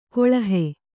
♪ koḷahe